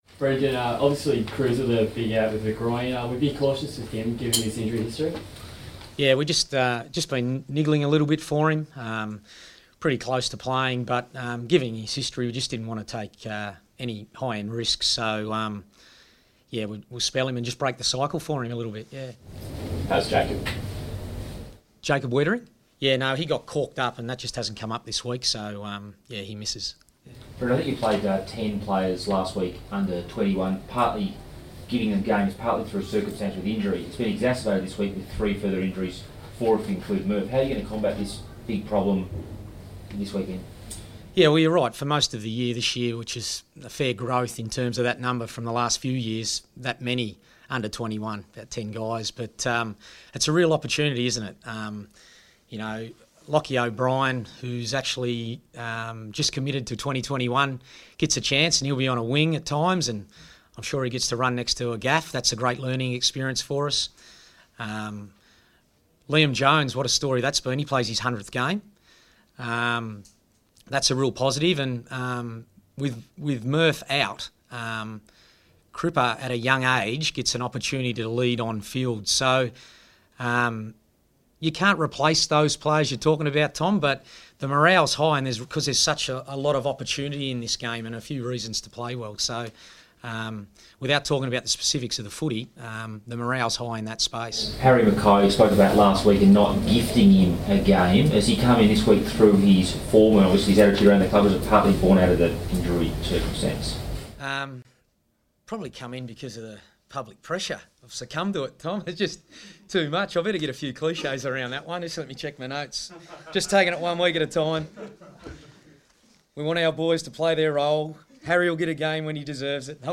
Brendon Bolton press conference | April 20
Carlton coach Brendon Bolton fronts the media at Ikon Park on the eve of the Blues' clash with West Coast.